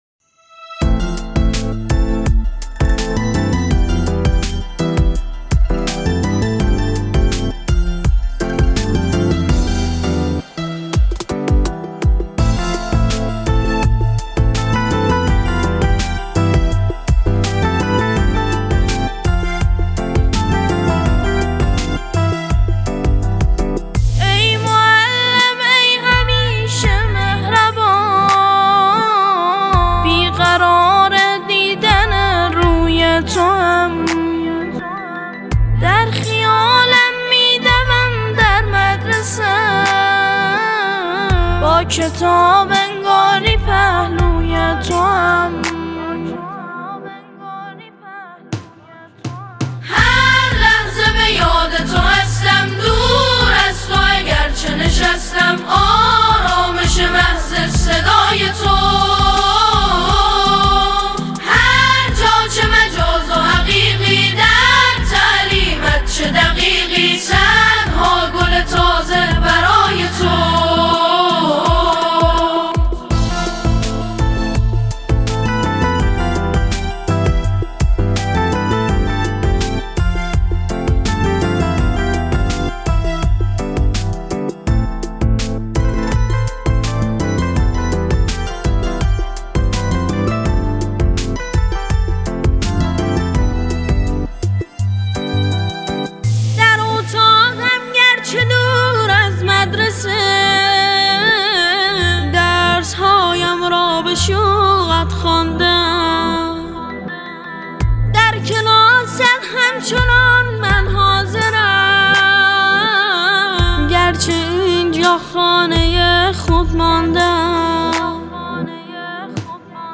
سرودهای روز معلم